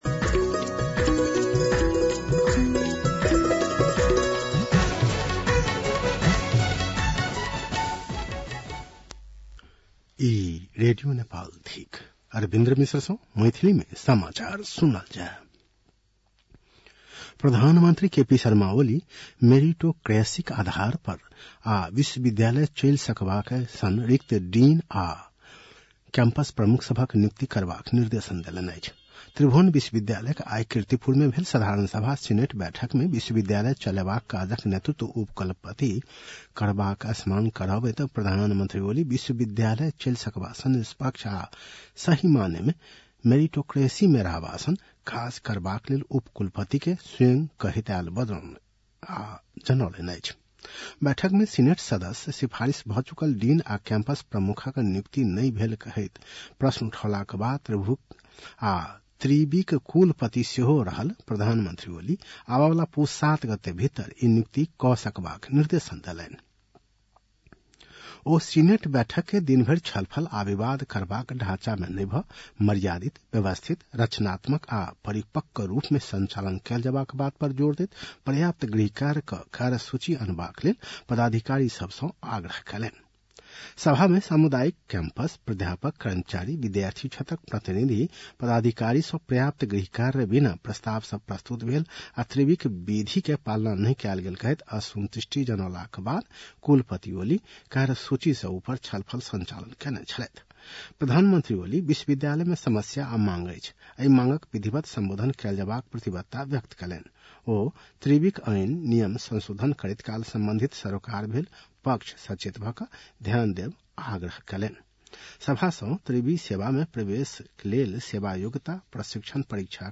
मैथिली भाषामा समाचार : २८ मंसिर , २०८१